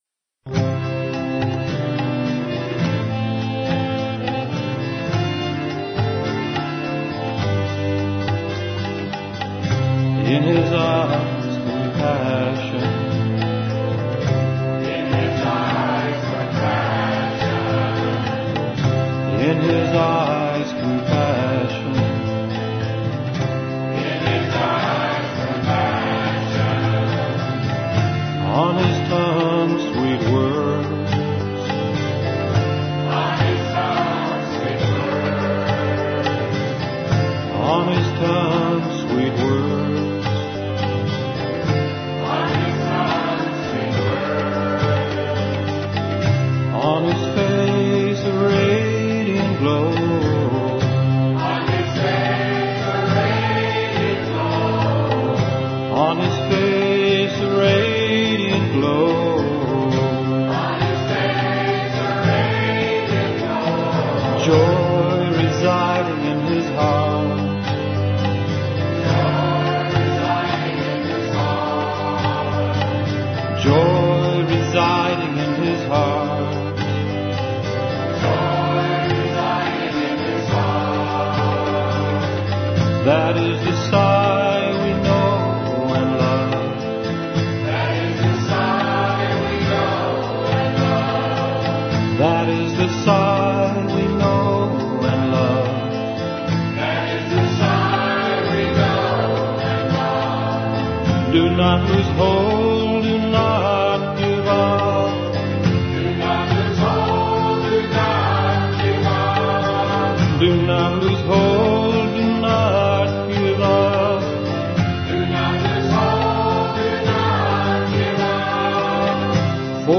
Devotional Songs
Major (Shankarabharanam / Bilawal)
8 Beat / Keherwa / Adi